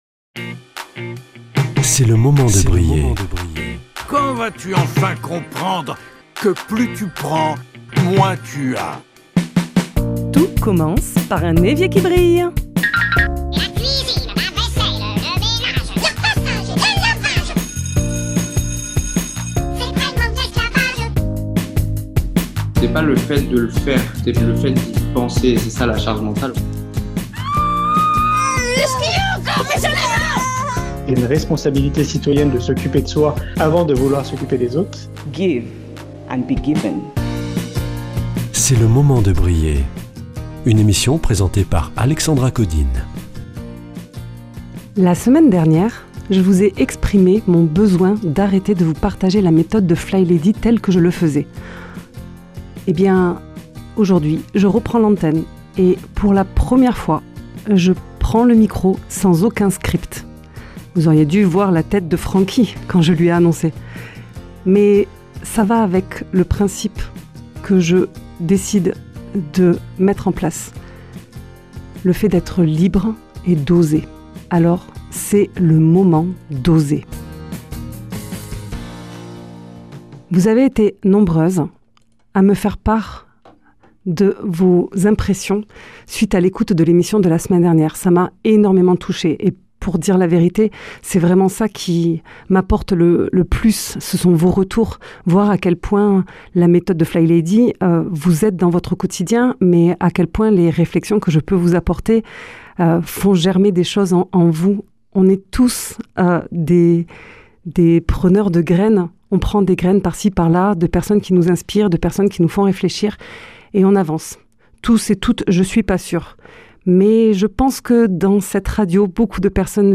Tester de se tromper, tester une experience sans scipt...